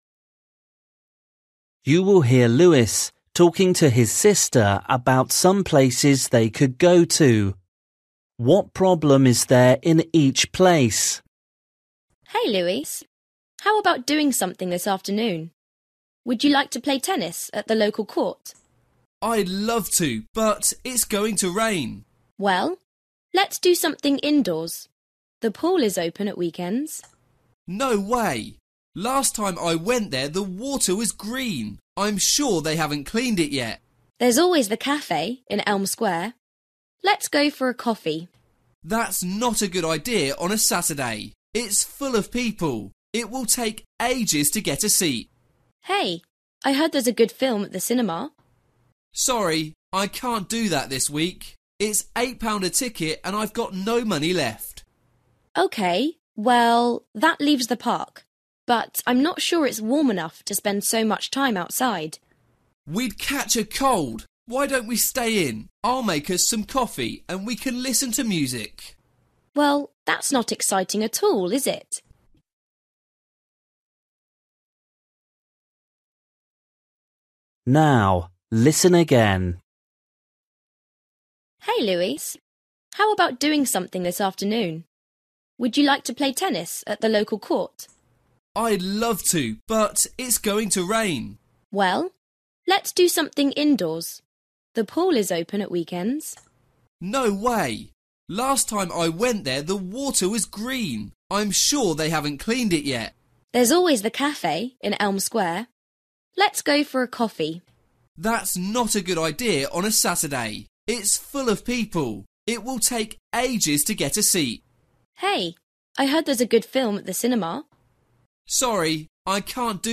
Luyện nghe trình độ A2